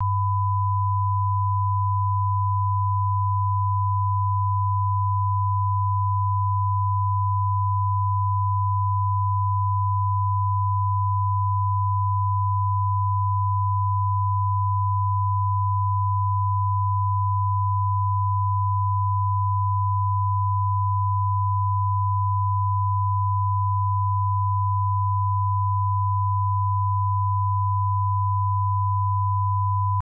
These test files contain -10dBFS 1kHz tone on the left channel and 100Hz tone on the right channel.
This was created with a professional Dolby Digital encoder (not available) and SMPTE 337 wrapping tool (available at Dolby's github repository). 2020-07-30 13:27:43 -07:00 5.5 MiB Raw History